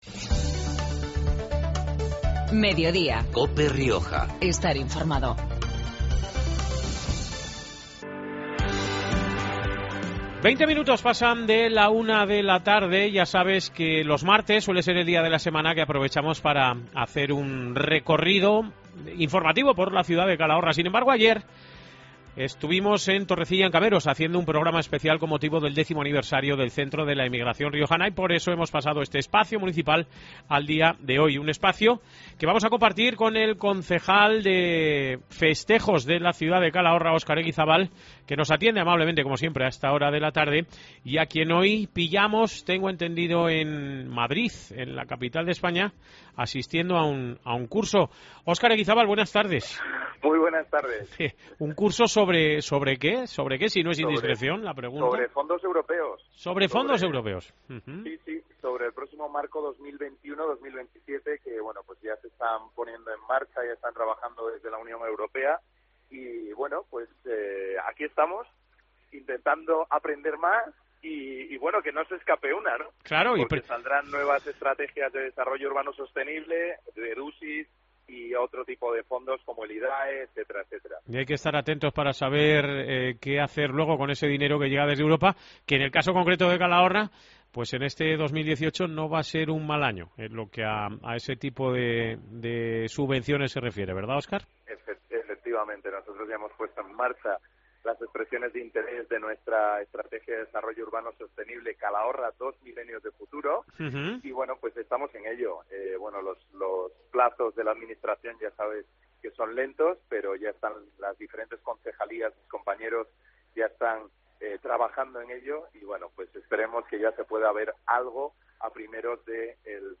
Herrera y Mediodía en Cope Rioja Baja (miércoles, 14 noviembre). Programa comarcal de actualidad, entrevistas y entretenimiento.
El motivo de este adelanto lo explicaba este mediodía en Cope Rioja Óscar Eguizábal, concejal de Festejos. Por tanto, el carnaval calagurritano, inspirado en el mundo de los cuentos, será del 15 al 17 de febrero, y las fiestas patronales de invierno irán del 1 al 3 de marzo.